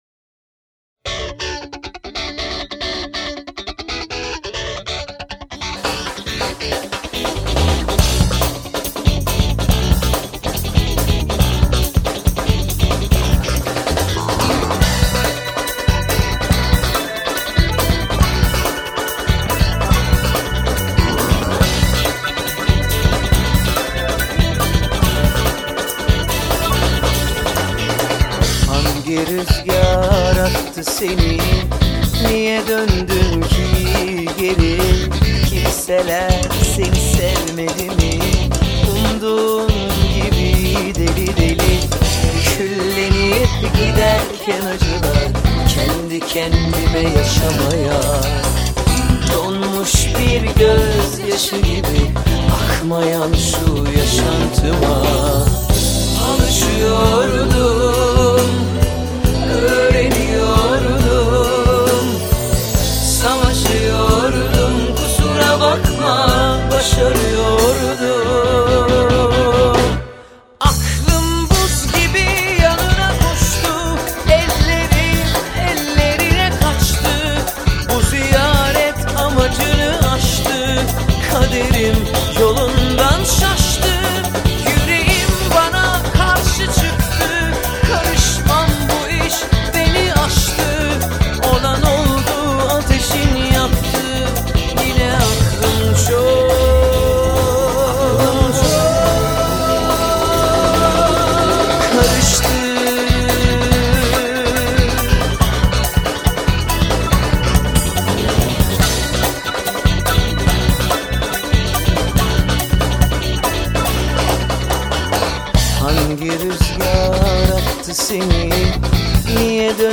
Drum & Base